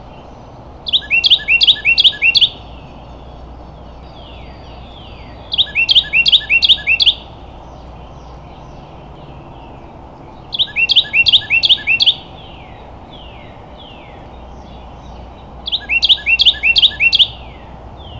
Here’s a Carolina Wren singing in our backyard.
carolina-wren.wav